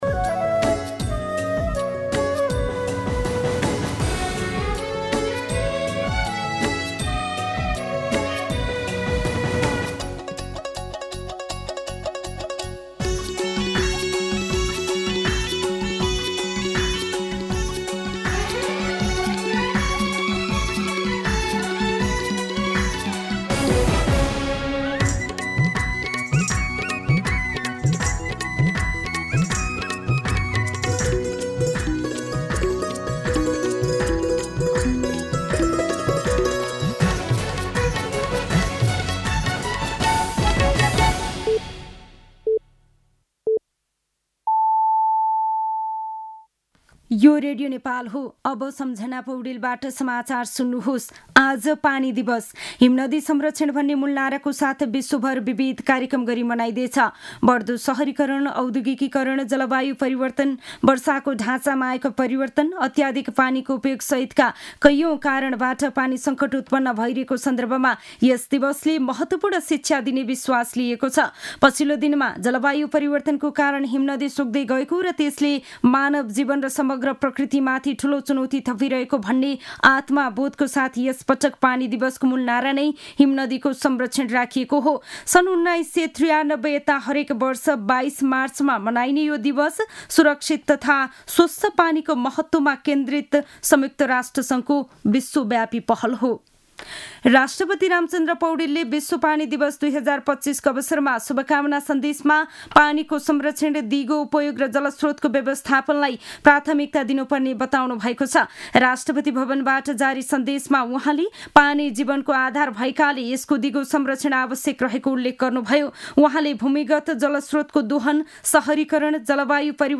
दिउँसो ४ बजेको नेपाली समाचार : ९ चैत , २०८१
4-pm-News-12-9.mp3